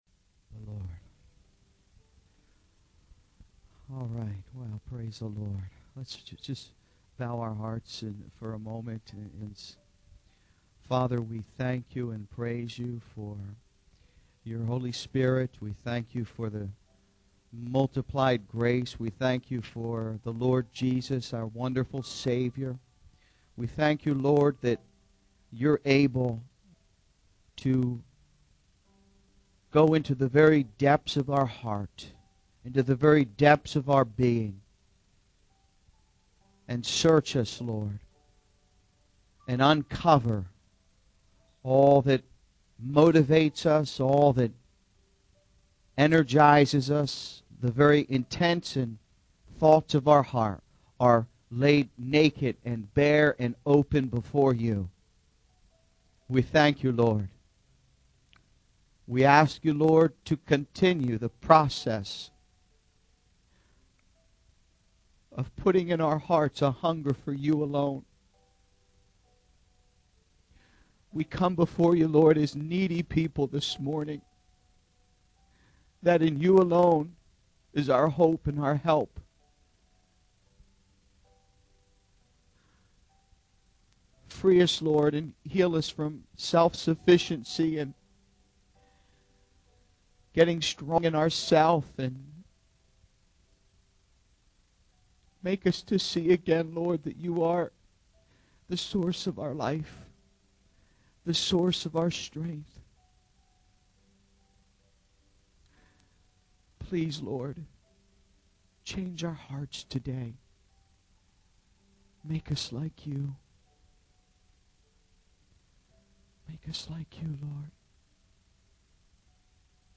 He uses the example of Job, who learned that true satisfaction comes from knowing God rather than pursuing worldly desires. The sermon highlights the importance of humility and the need for believers to serve others selflessly, as demonstrated by Jesus washing His disciples' feet.